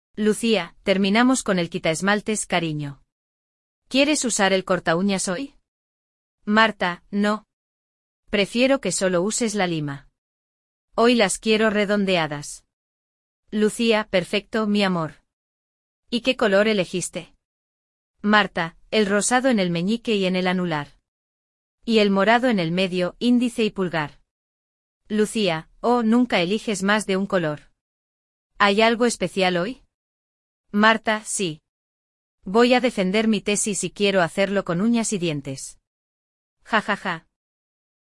‍Diálogo